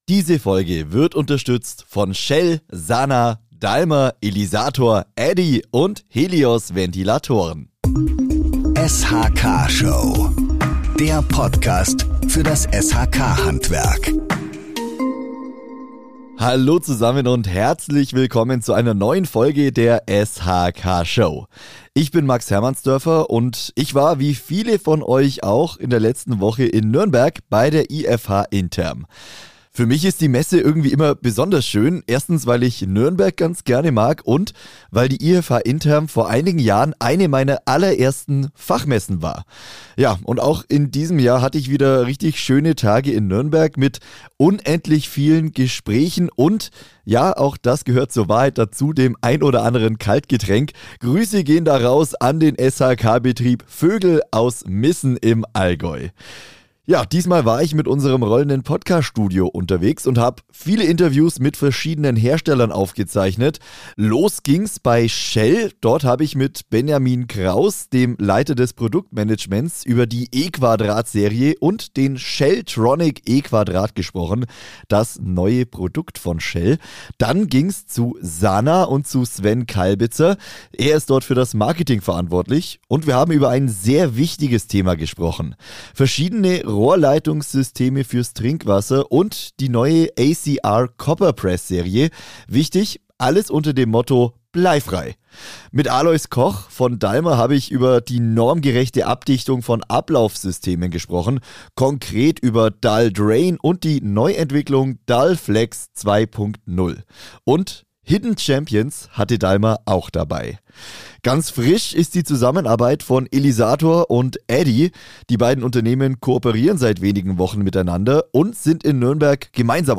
Mit rund 38.000 Besuchern und über 400 Ausstellern bot die Messe einen umfassenden Überblick über aktuelle Trends, Technologien und Herausforderungen – von Digitalisierung über Trinkwasserhygiene bis hin zur Wärmewende. Genau diese Themen greifen wir in dieser Podcastfolge mit dem rollenden Podcaststudio auf.